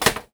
R - Foley 119.wav